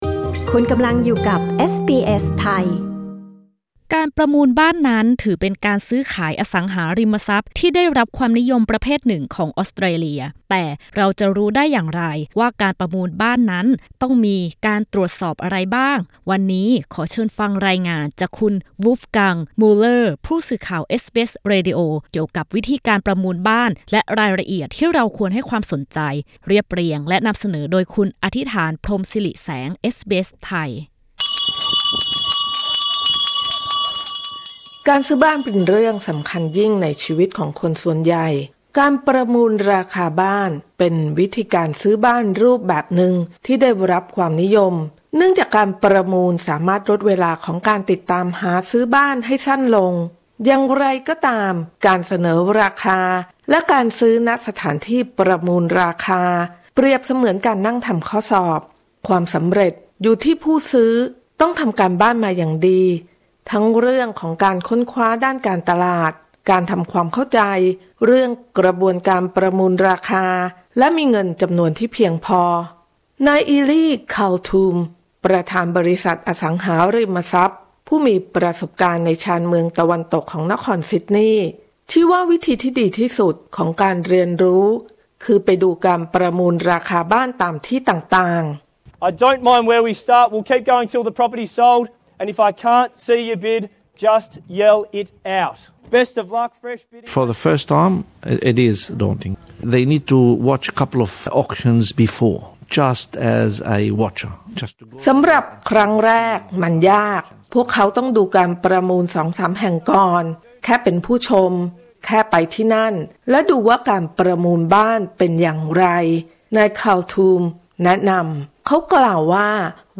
กดปุ่ม 🔊 ด้านบนเพื่อฟังรายงานเรื่องนี้